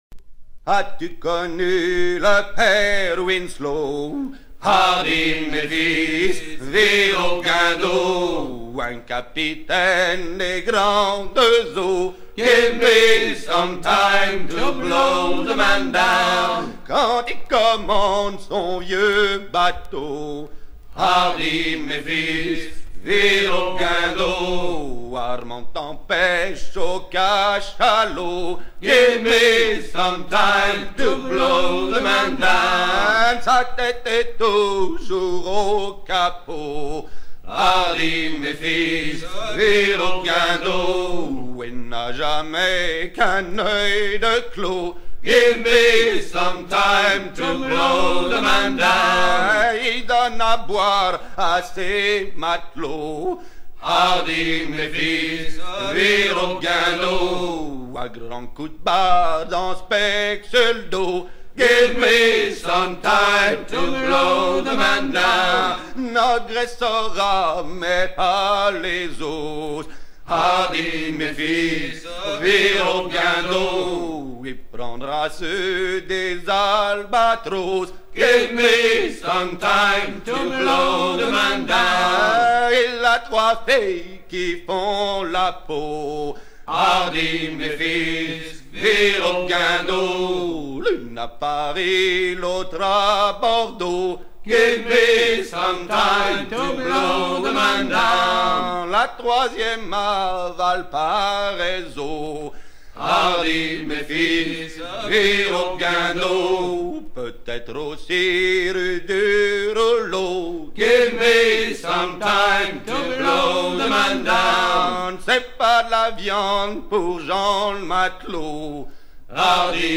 Fonction d'après l'analyste gestuel : à virer au guindeau
Genre laisse
Pièce musicale éditée